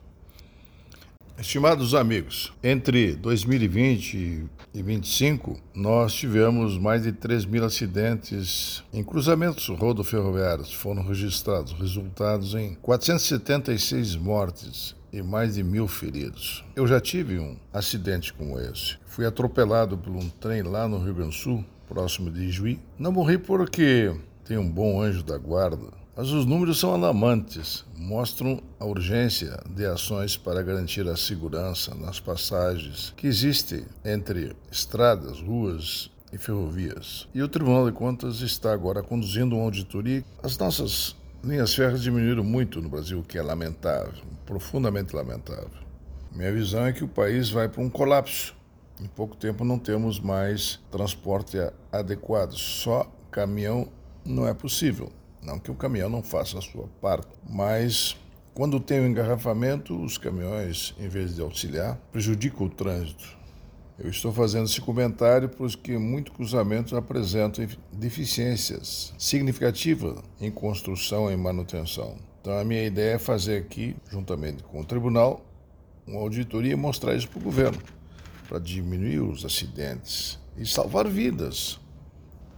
Comentário de Augusto Nardes, ministro do Tribunal de Contas da União.